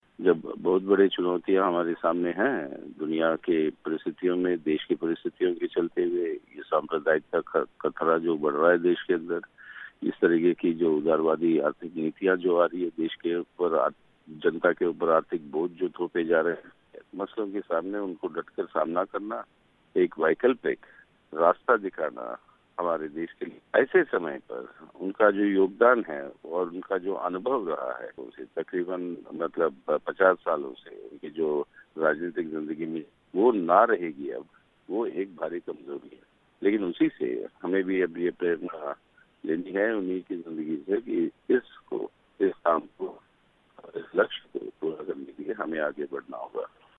एबी बर्धन के ना रहने के बाद अब भारतीय कम्यूनिस्ट पार्टी के सामने क्या हैं चुनौतियां. सीपीआईएम के वरिष्ठ नेता सीताराम येचुरी से बात की